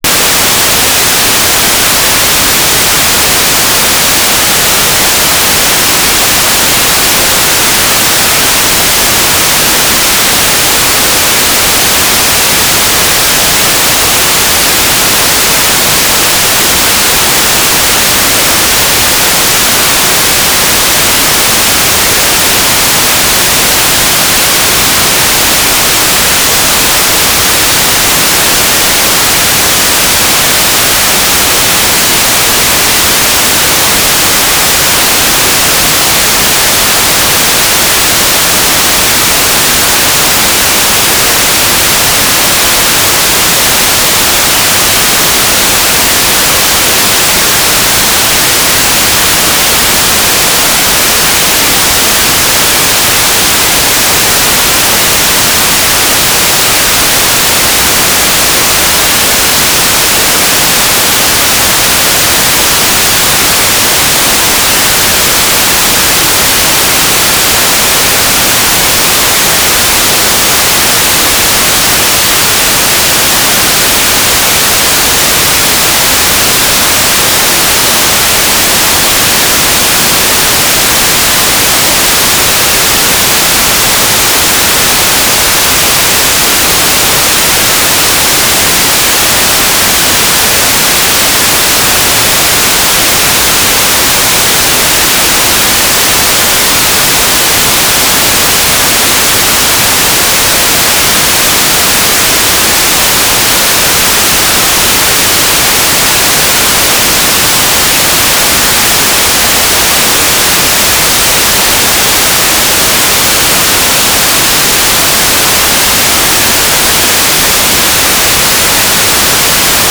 "station_name": "Sternwarte Sonneberg Turnstile VHF (Test UHF)",
"transmitter_description": "Mode U - GMSK2k4 - USP",
"transmitter_mode": "GMSK USP",